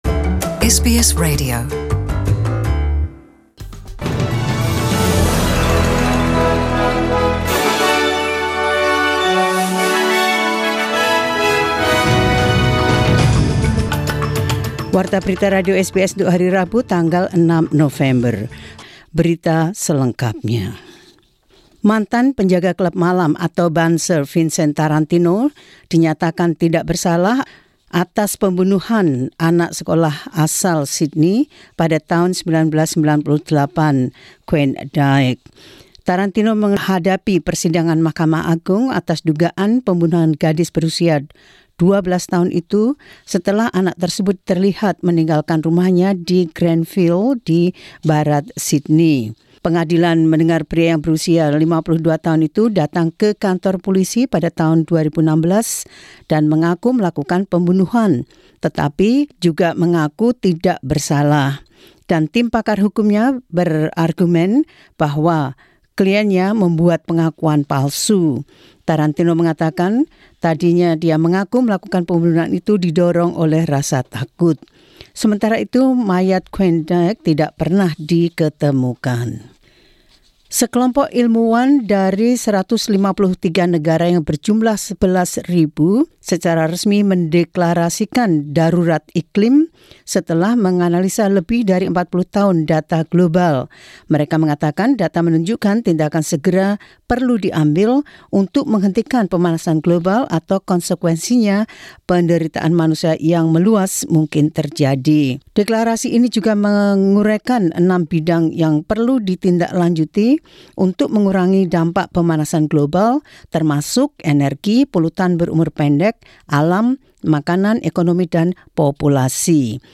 SBS Radio News in Indonesian 6 Nov 2019.